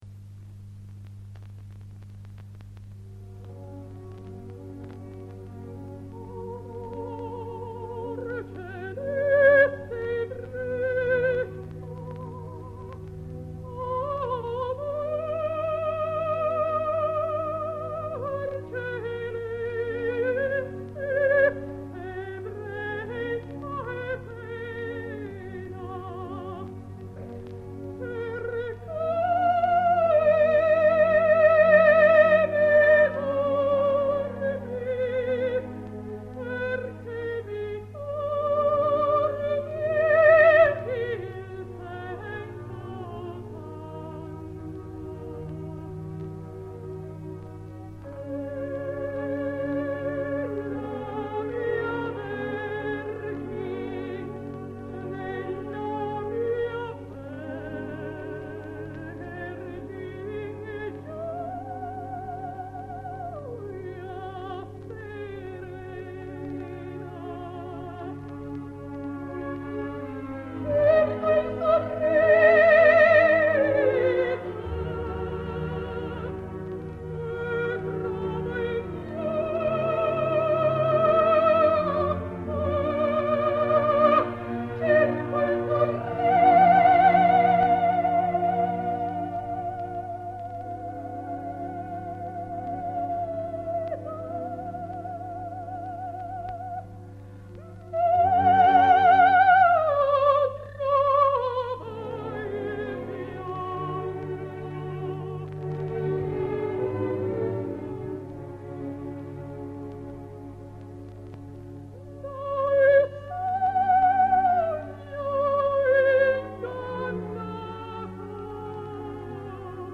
Ascolta la sua voce!
Un profilo sul grande soprano